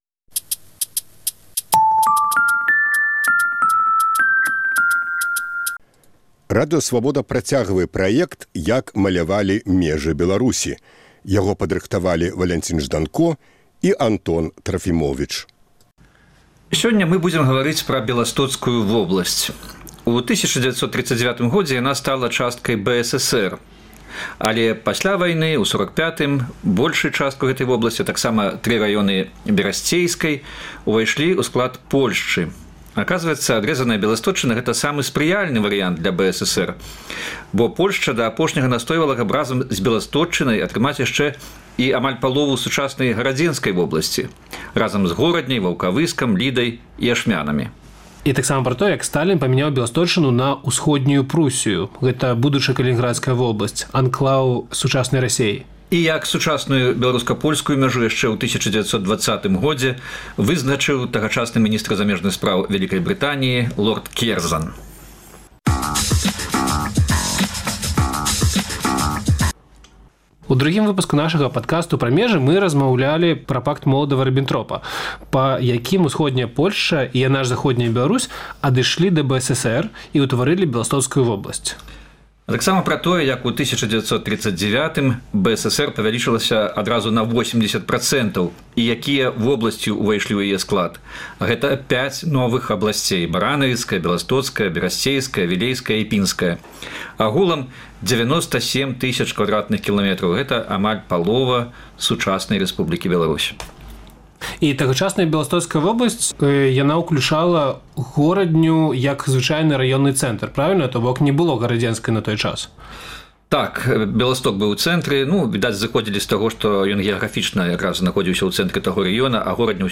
Штотыднёвы круглы стол экспэртаў і аналітыкаў на актуальную тэму.